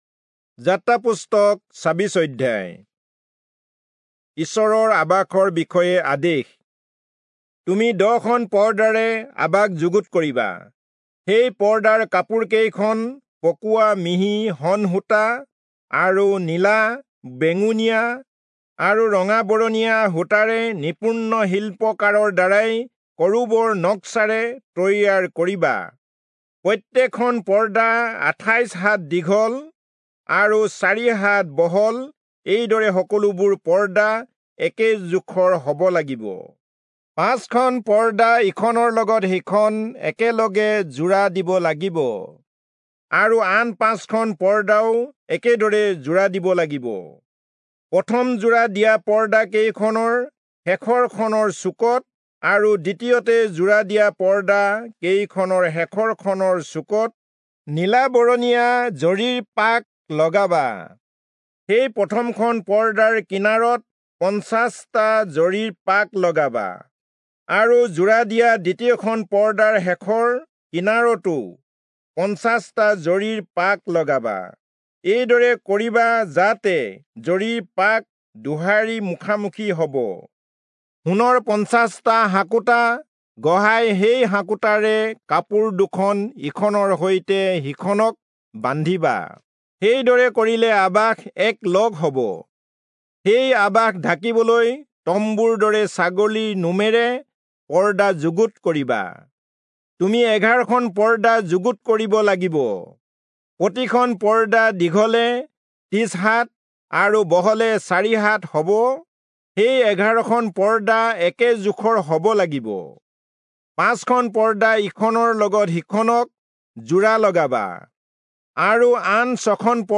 Assamese Audio Bible - Exodus 40 in Hov bible version